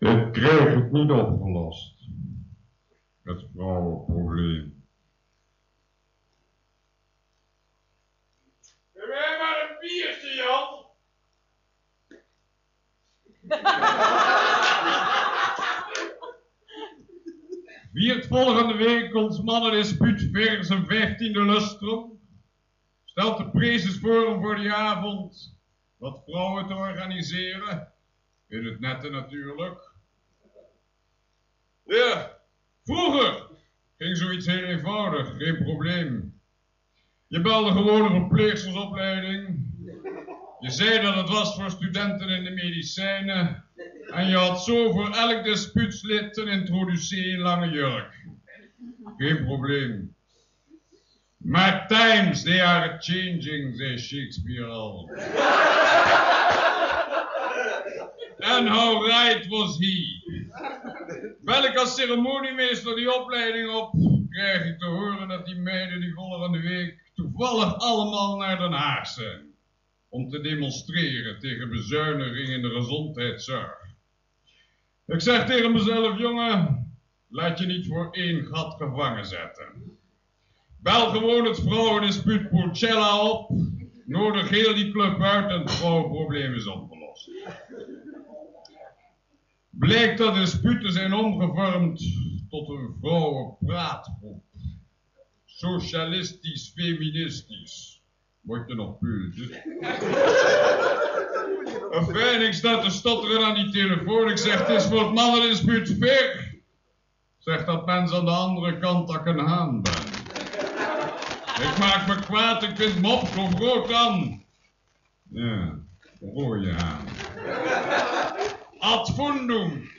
Opname tijdens een Try Out met een cassetterecorder, matige kwaliteit.